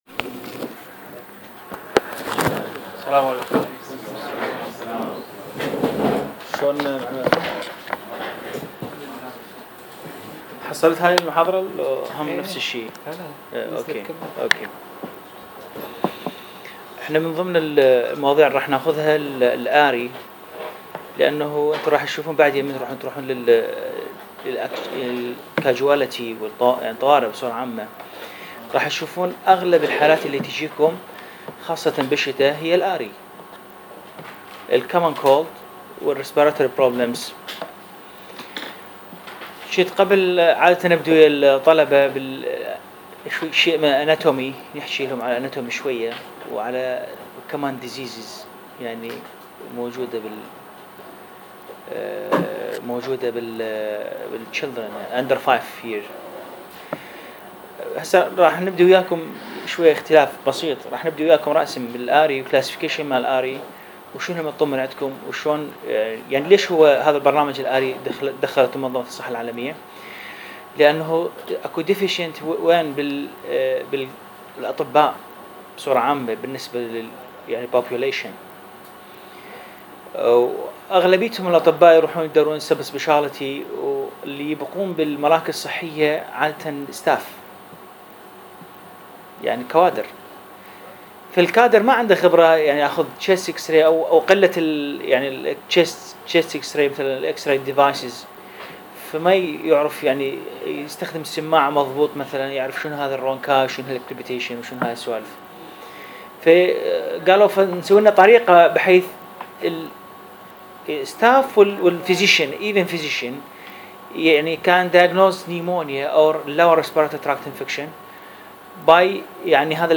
محاضرات المركز الصحي ( WHO )